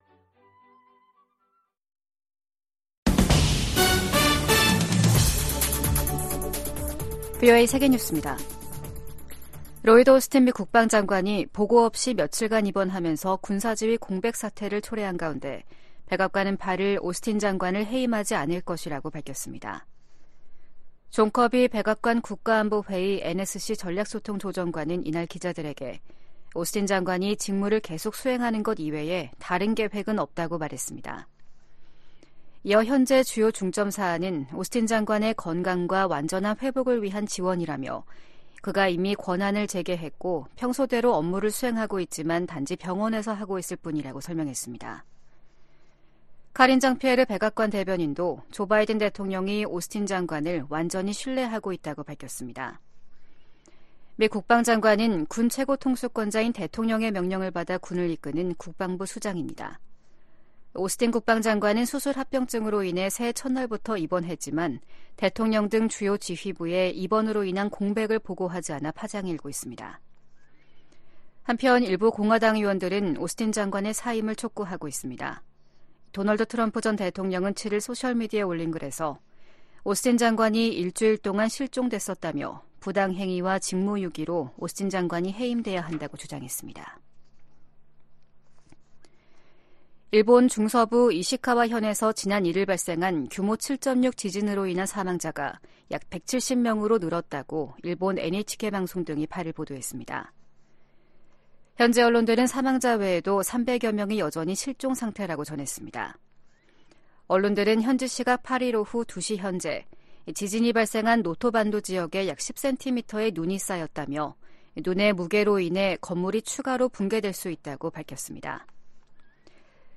VOA 한국어 아침 뉴스 프로그램 '워싱턴 뉴스 광장' 2024년 1월 9일 방송입니다. 북한 군이 한국의 서북도서 인근에서 포 사격을 실시하자 한국 군도 해당 구역 군사훈련을 재개하기로 했습니다. 미 국무부는 북한의 서해 해상 사격에 도발 자제와 외교적 해결을 촉구했습니다. 미국 정부는 팔레스타인 무장정파 하마스가 북한 로켓 부품을 이용해 신무기를 만들고 있는 것과 관련해, 북한은 오래 전부터 중동 지역에 무기를 판매해 오고 있다고 밝혔습니다.